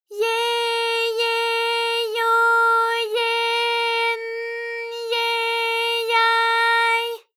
ALYS-DB-001-JPN - First Japanese UTAU vocal library of ALYS.
ye_ye_yo_ye_n_ye_ya_y.wav